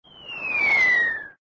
incoming_whistle.ogg